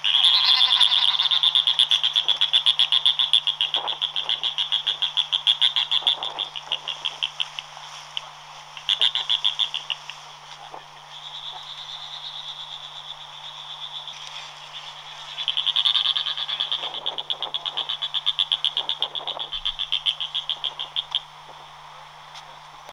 Thalassarche steadi - Albatros corona blanca
Thalassarche steadi - Albatros corona blanca.wav